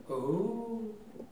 Les sons ont été découpés en morceaux exploitables. 2017-04-10 17:58:57 +02:00 228 KiB Raw History